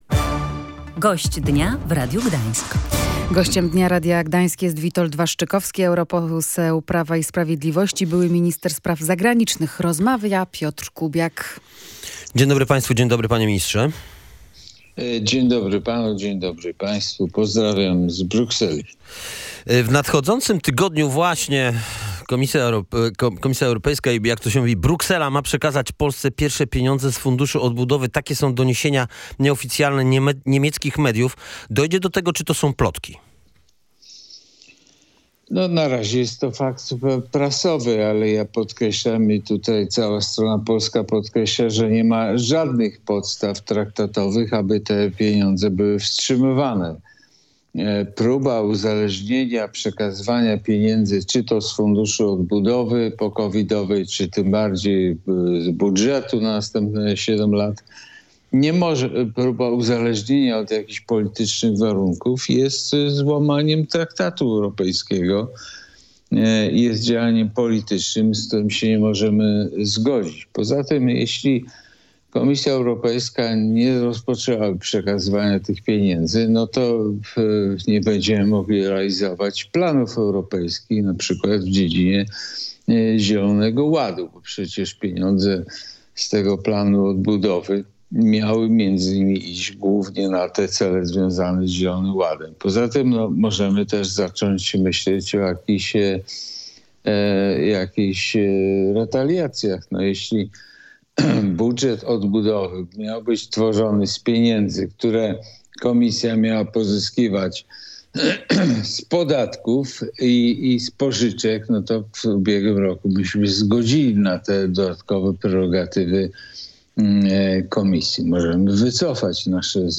Wypłaty środków z funduszu odbudowy oraz wizyta w Polsce nowego kanclerza Niemiec Olafa Scholza były jednymi z tematów rozmowy z